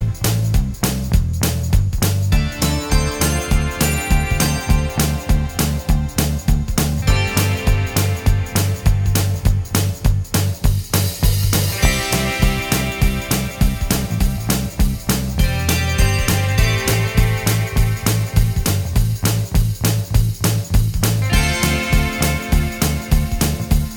Minus Guitars Pop (1980s) 3:49 Buy £1.50